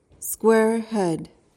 PRONUNCIATION: (SKWAIR-hed) MEANING: noun: 1.
squarehead.mp3